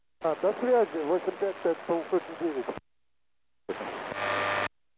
Жужжущая помеха в АМ
pomeha.wav